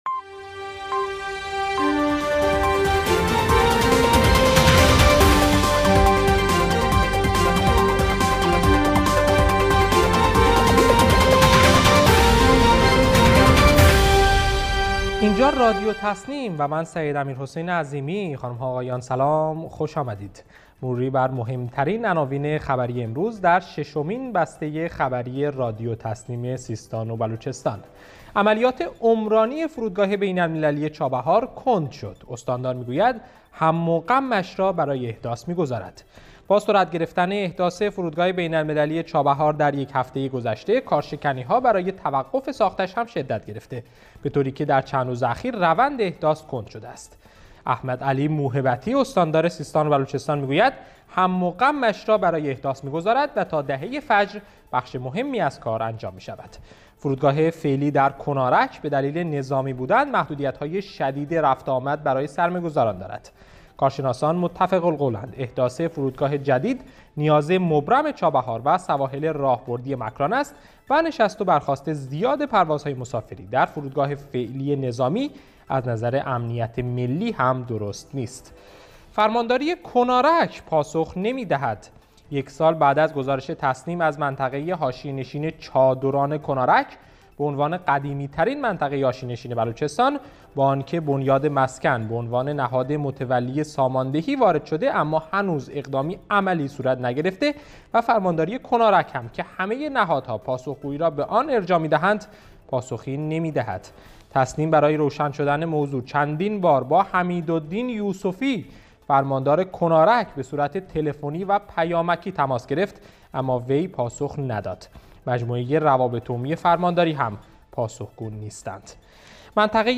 گروه استان‌ها- در ششمین بسته خبری رادیو تسنیم سیستان و بلوچستان با مهم‌ترین عناوین خبری امروز همراه ما باشید.